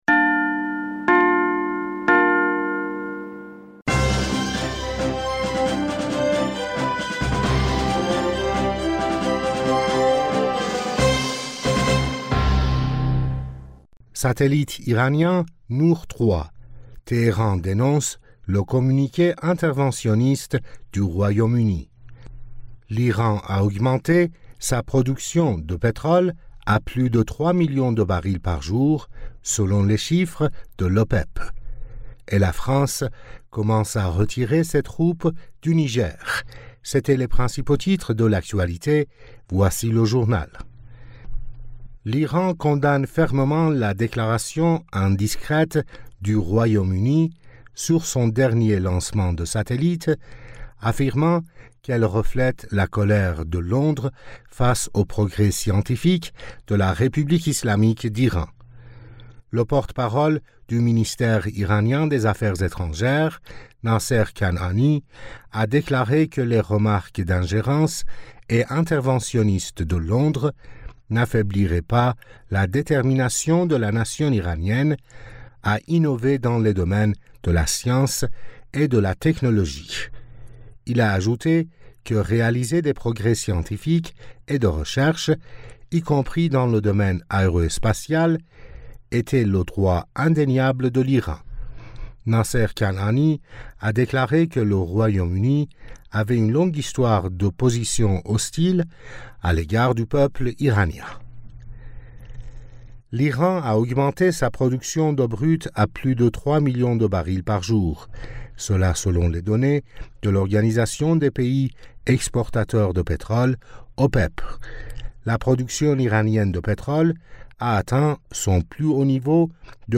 Bulletin d'information du 05 Octobre 2023